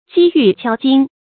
擊玉敲金 注音： ㄐㄧ ㄧㄩˋ ㄑㄧㄠ ㄐㄧㄣ 讀音讀法： 意思解釋： 如金玉被撞擊而發出的聲音。